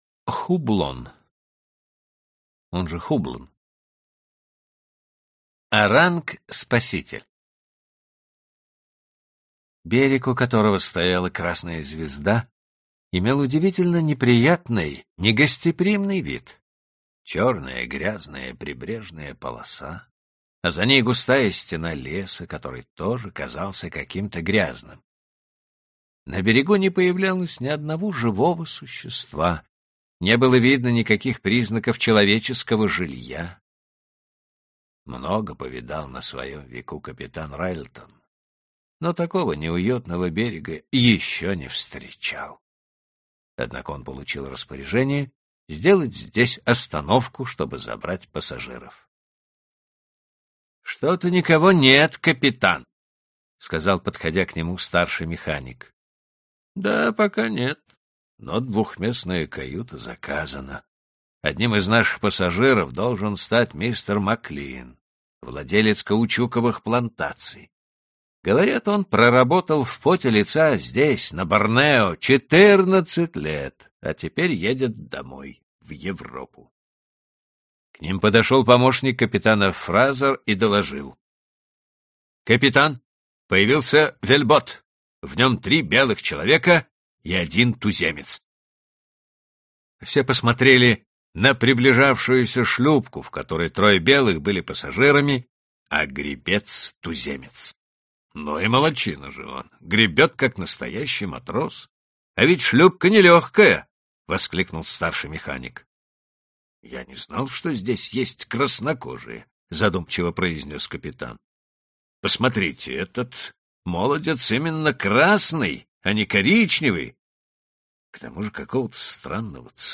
Аудиокнига Классика зарубежного рассказа № 18 | Библиотека аудиокниг